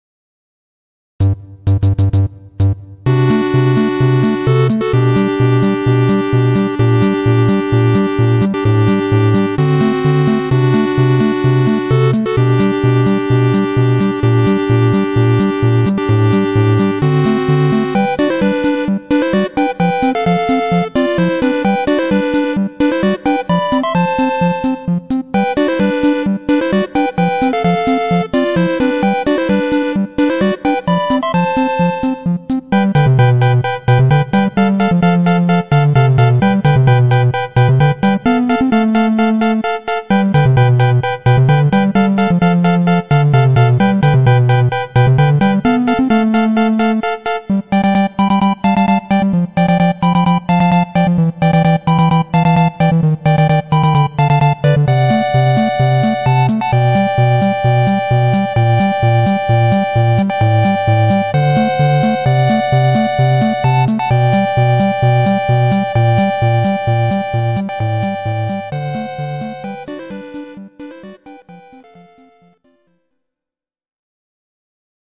２ループ目から主旋律は１オクターブ高くなる。GS音源。